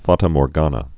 (fätə môr-gänə)